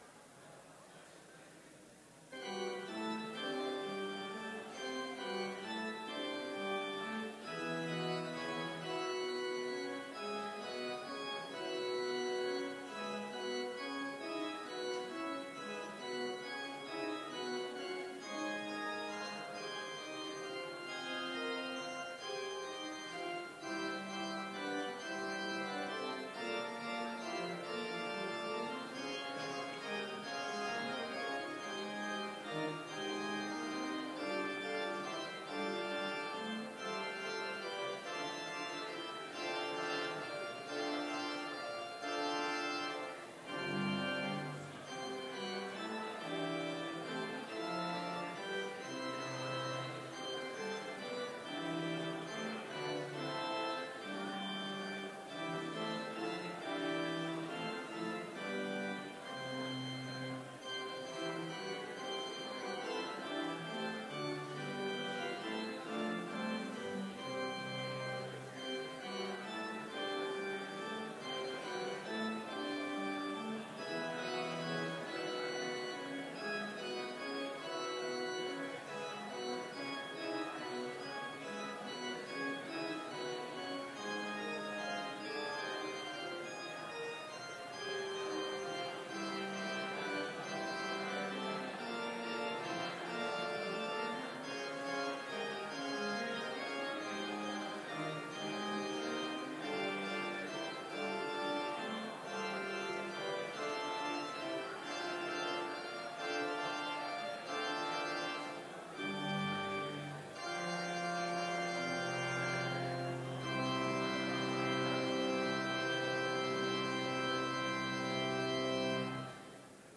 Complete service audio for Chapel - May 3, 2019